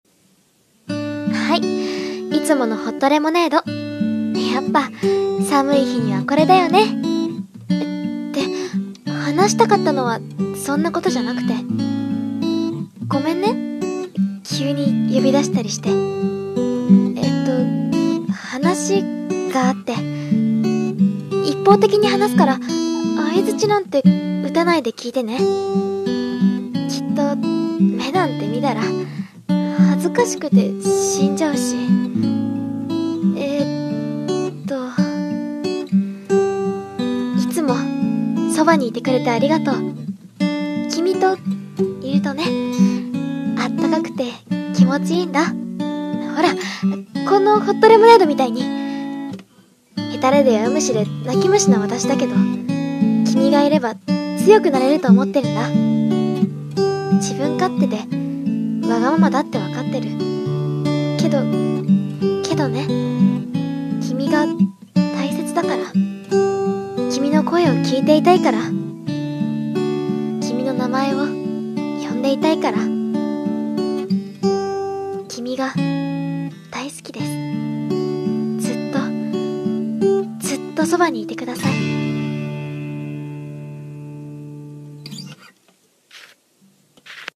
声劇】ホットレモン